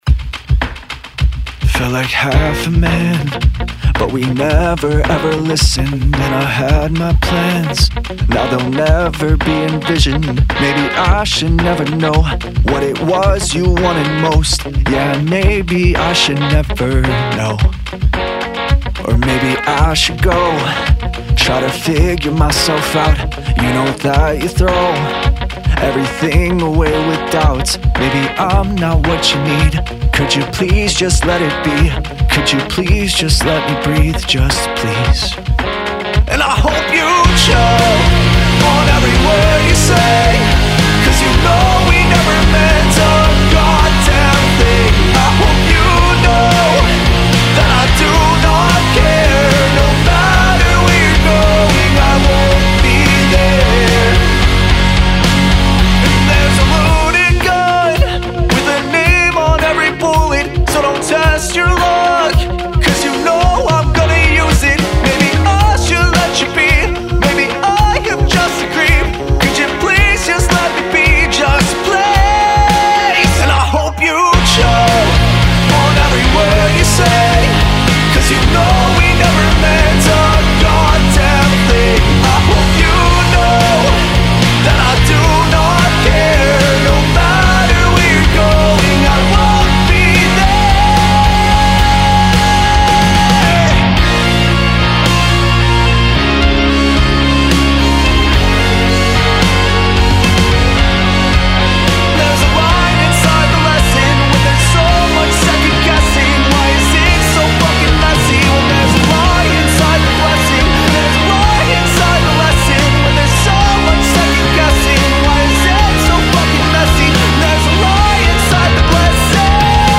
A very angry rock ballad with hyping vibes.